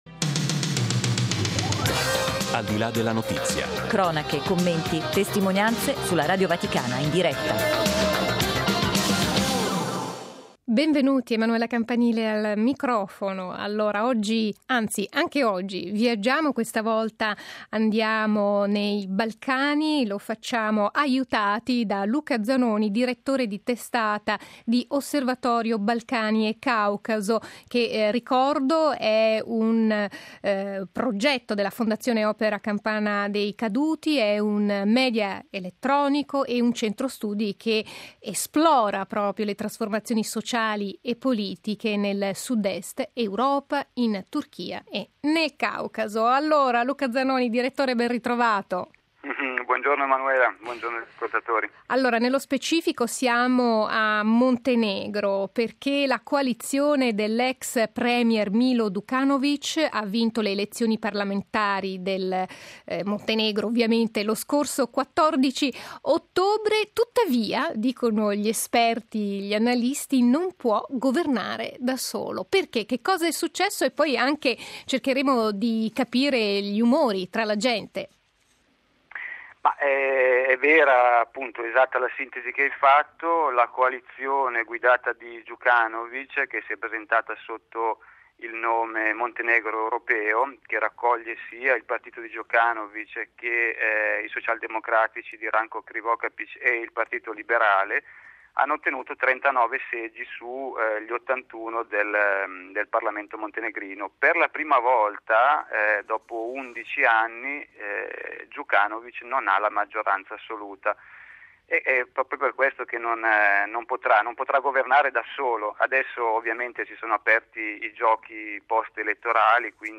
Altra sfida, quella della crisi e della disoccupazione nel Paese. Ne abbiamo parlato con alcuni analisti del centro studi Osservatorio Balcani Caucaso.